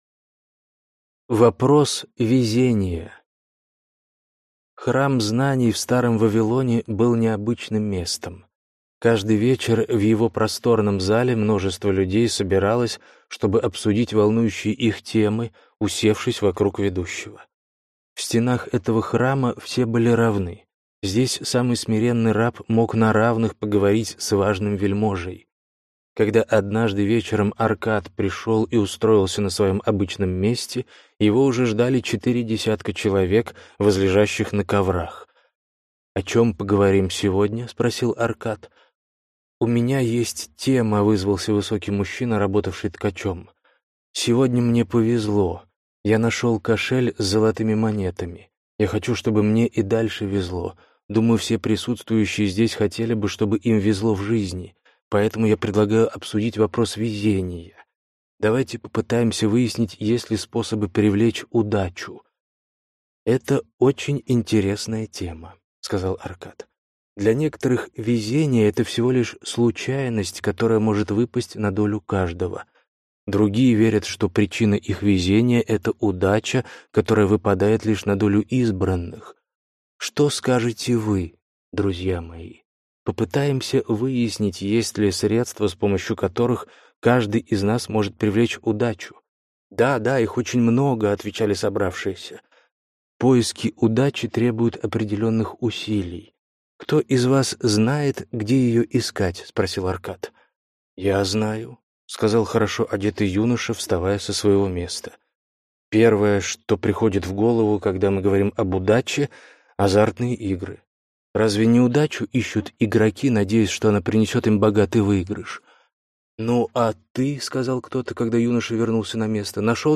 Аудиокнига Самый богатый человек в Вавилоне | Библиотека аудиокниг